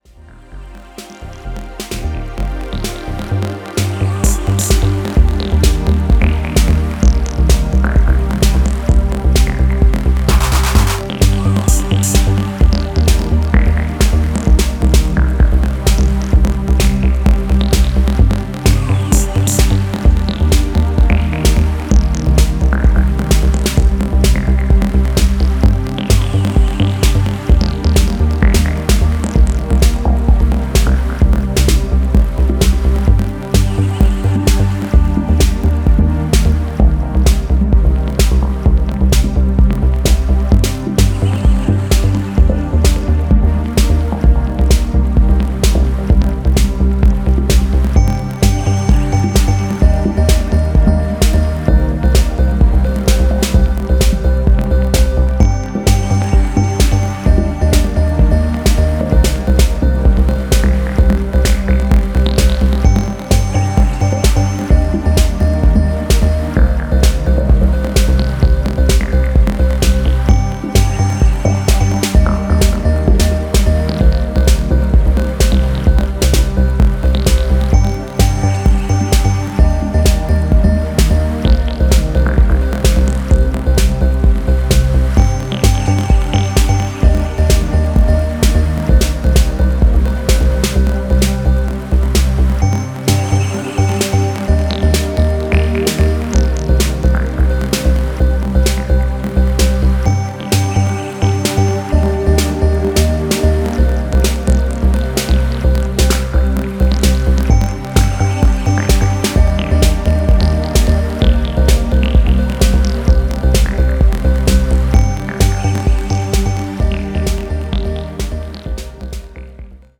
colourful and imaginative take on deep techno